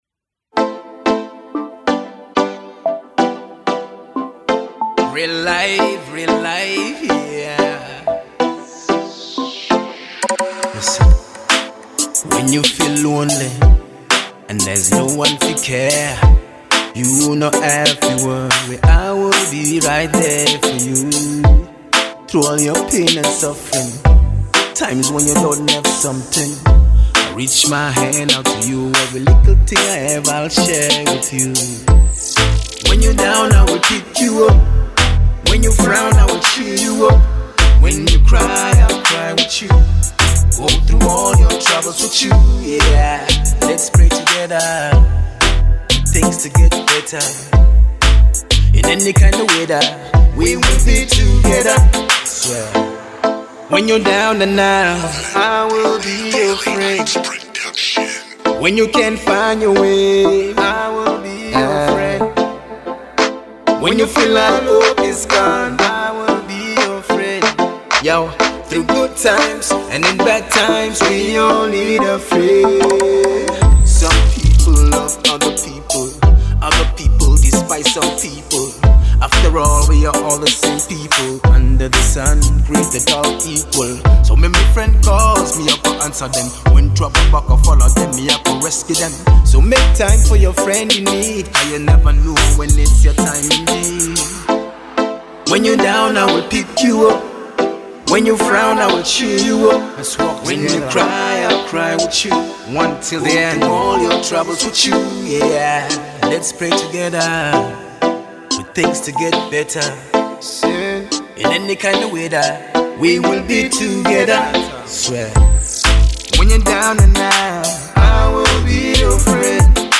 a blend of dancehall, hip hop, and Afro-fusion